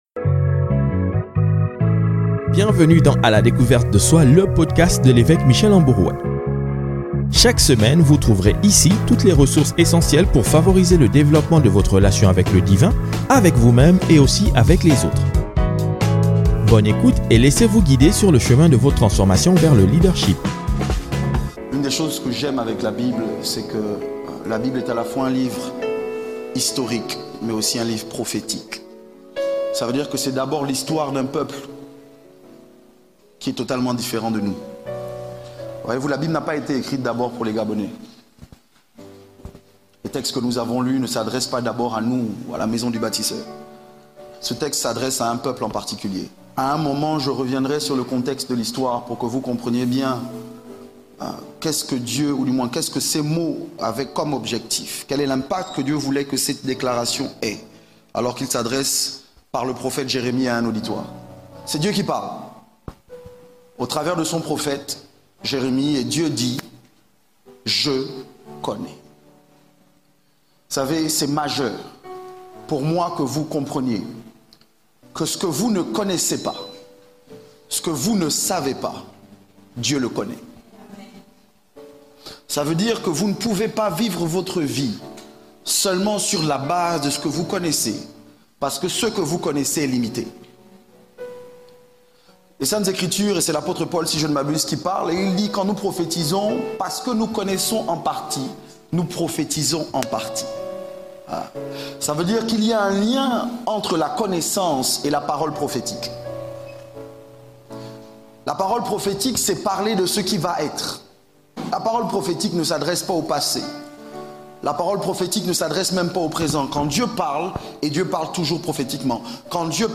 Dieu peut tout... Cette phrase suffit à elle seule pour résumer le message contenu dans ce nouvel enseignement.